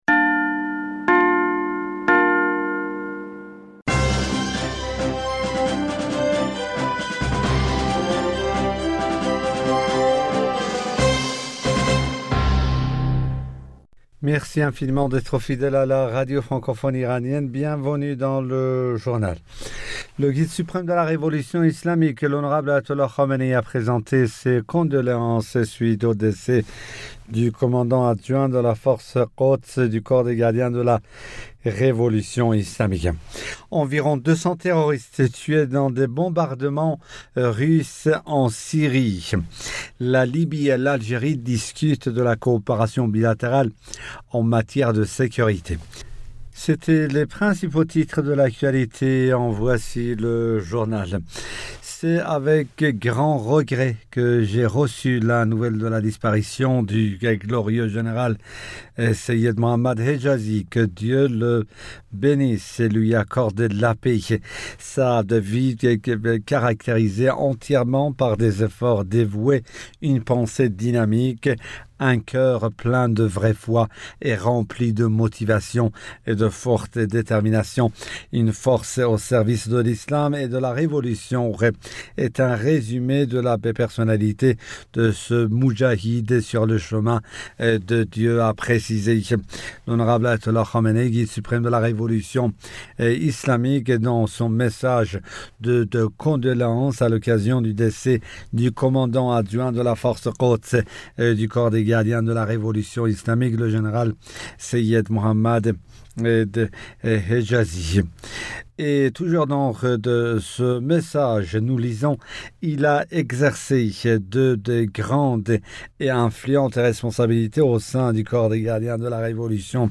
Bulletin d'information du 20 Avril 2021